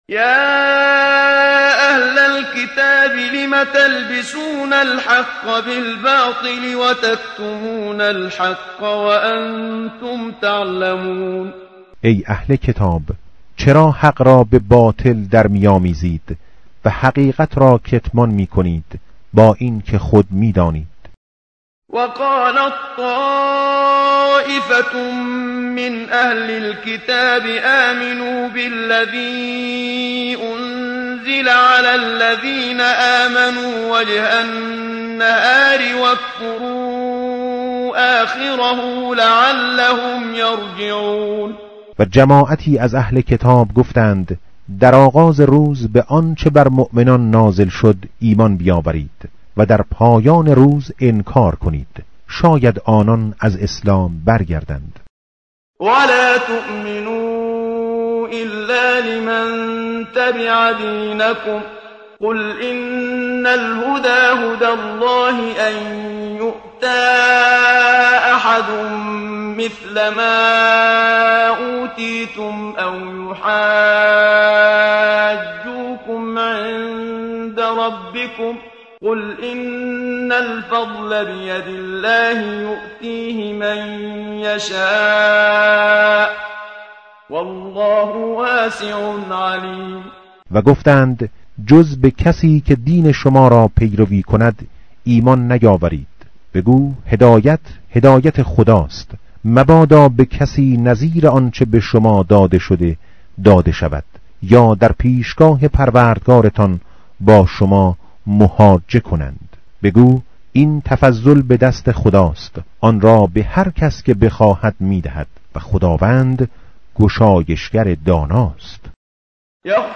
متن قرآن همراه باتلاوت قرآن و ترجمه
tartil_menshavi va tarjome_Page_059.mp3